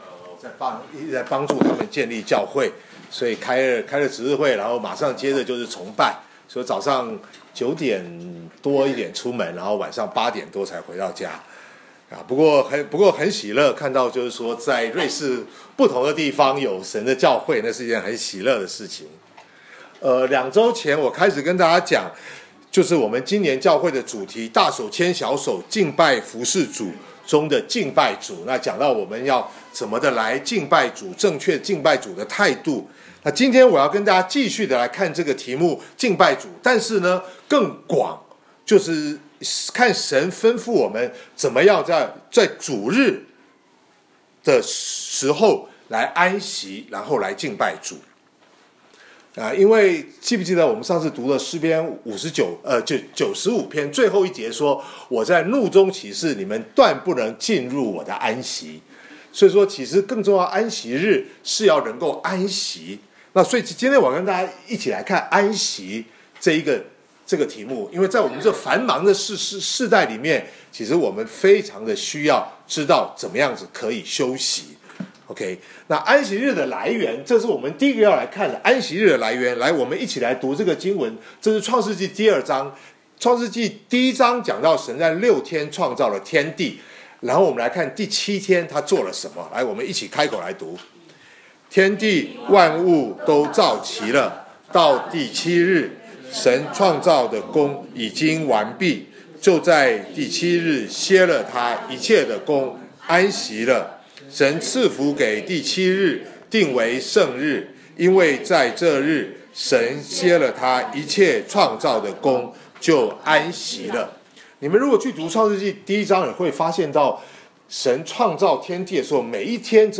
2019年1月27日主日讲道